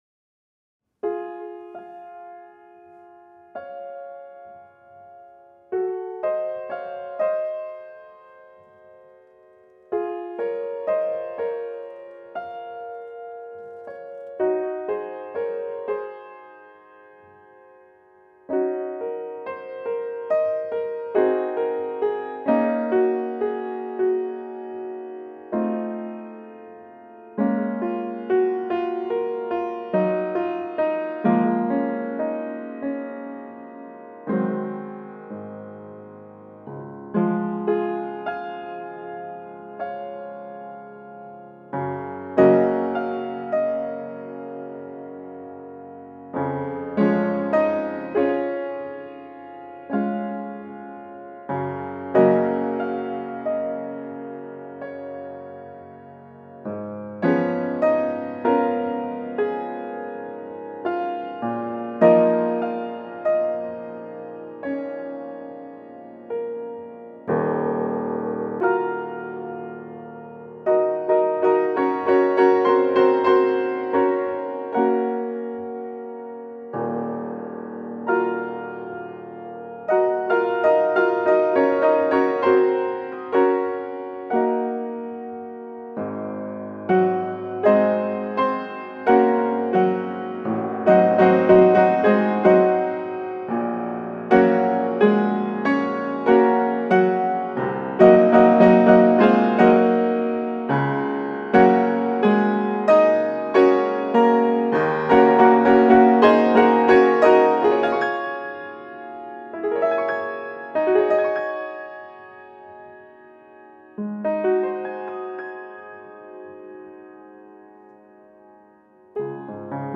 Enregistrement de piano en 2017-18 dans le studio de l'école de musique de la CCMP (01)